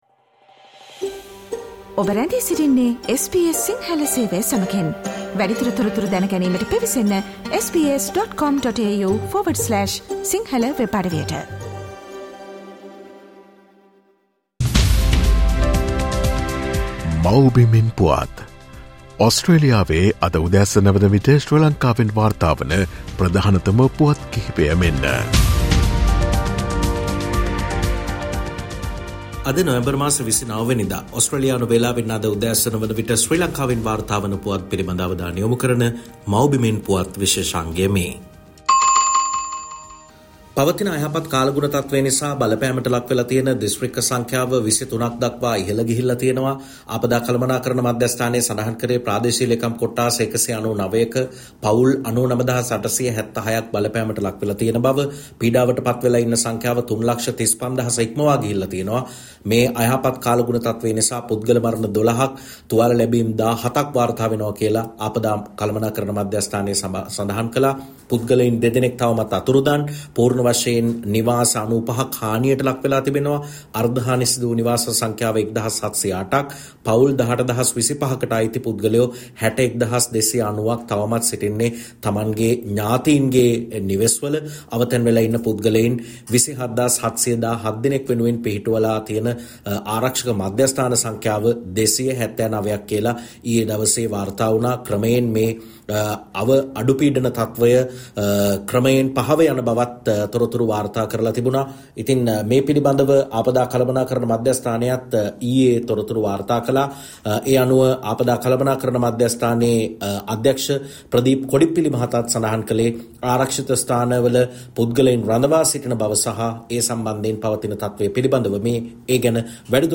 Here are the most prominent News Highlights of Sri Lanka.